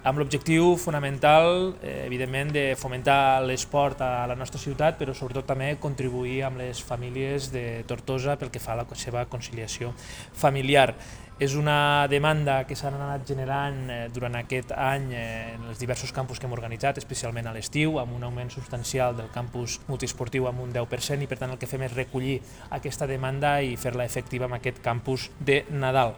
El regidor d’Esports i president de Tortosaesport, Víctor Grau, ha subratllat que aquest projecte neix per donar resposta a la demanda de les famílies i per oferir als infants una opció educativa i divertida durant les vacances escolars